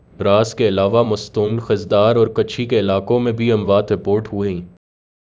Spoofed_TTS/Speaker_11/264.wav · CSALT/deepfake_detection_dataset_urdu at main